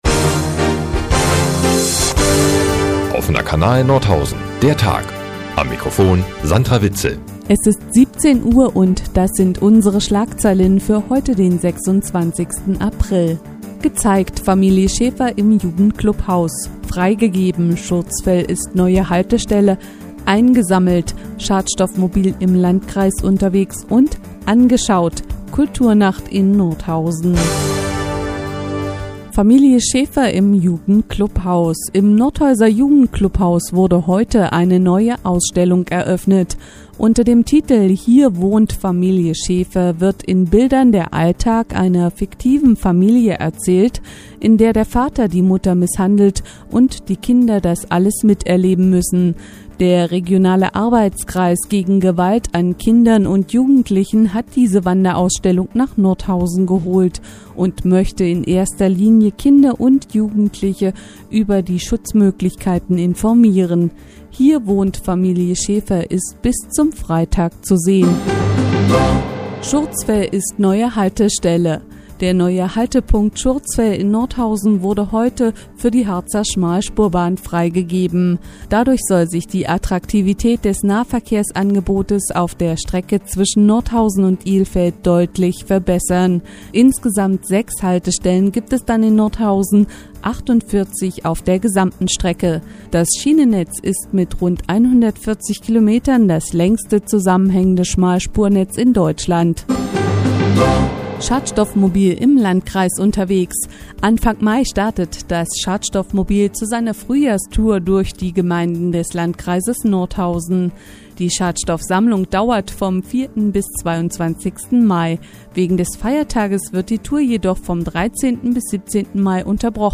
Die tägliche Nachrichtensendung des OKN ist auch hier zu hören.